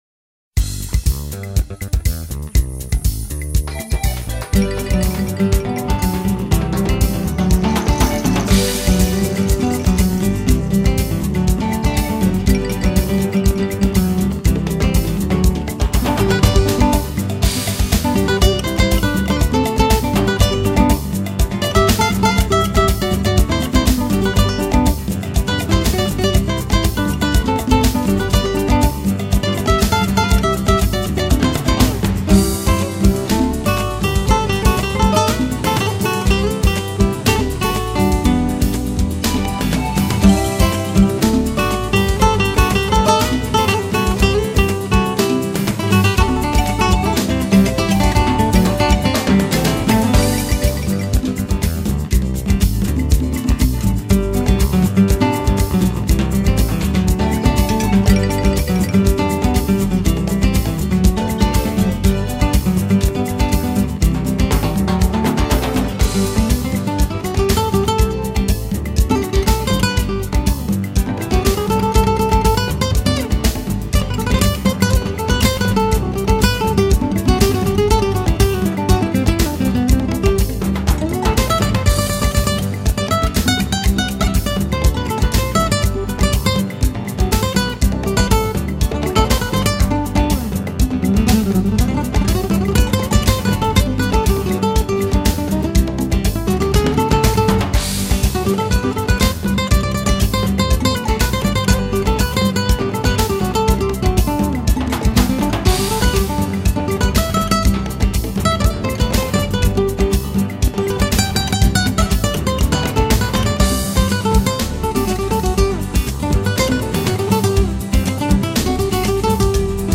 音乐是南太平洋岛屿不可缺的语言，透过热情洋溢的曲风，传达出岛国欢乐的生活哲学。